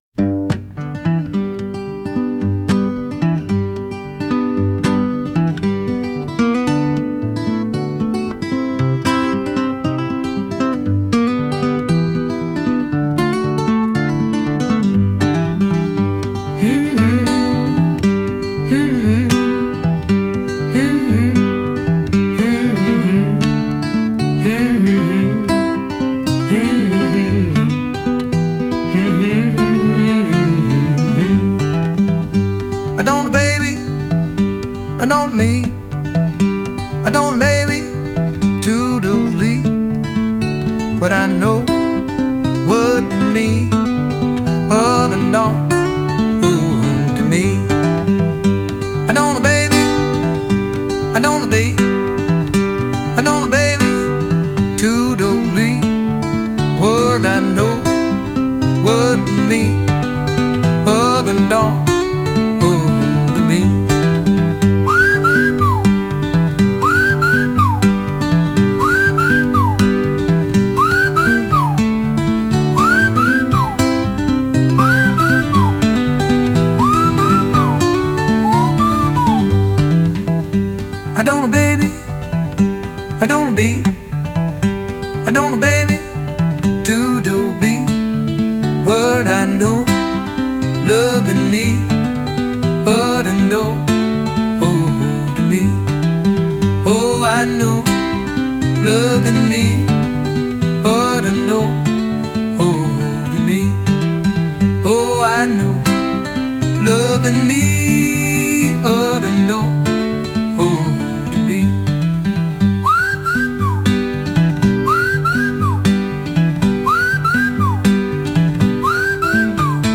Instrumental -Join Real Liberty Media DOT Com